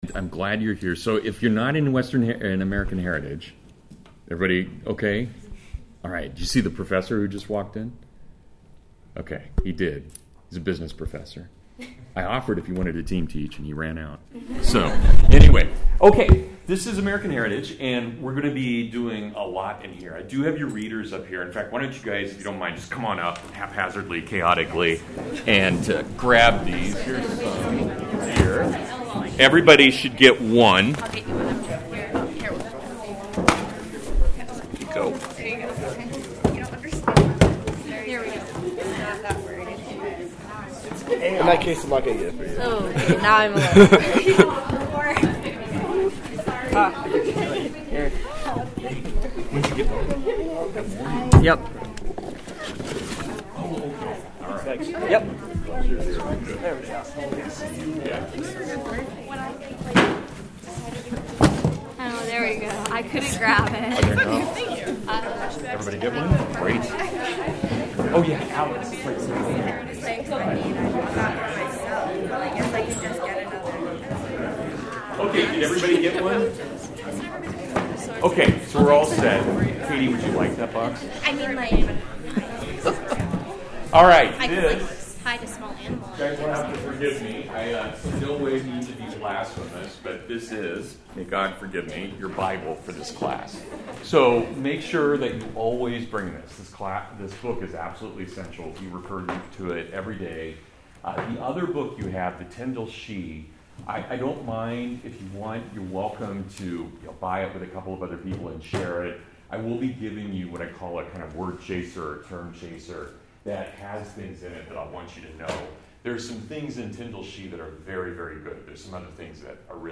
Intro to American History (Full Lecture)